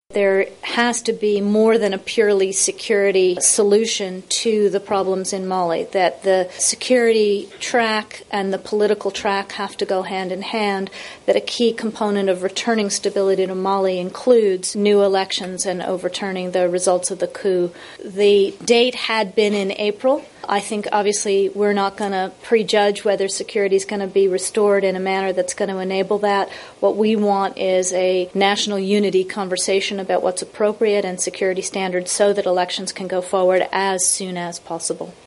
Victoria Nuland, porte-parole du Département d'Etat